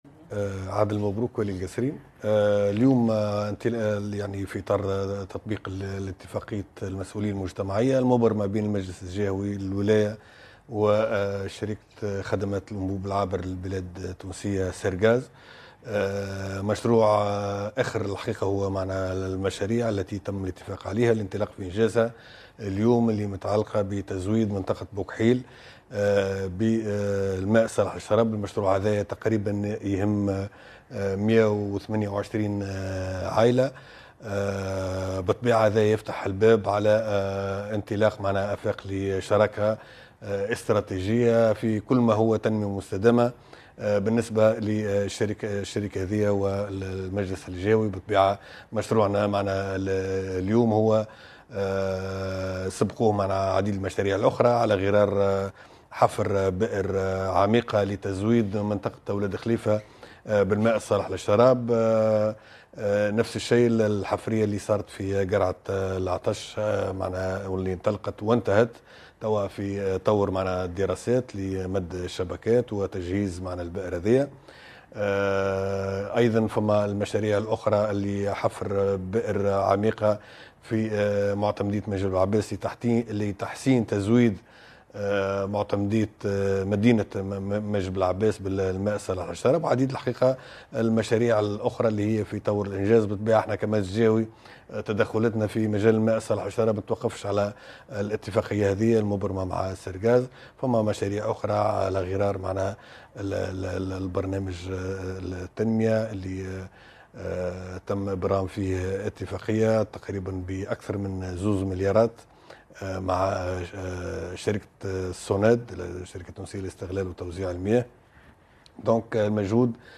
Les travaux prendront fin au mois de novembre 2022 a rapporté le gouverneur de Kasserine, Adel Mabrouk à Tunisie Numérique.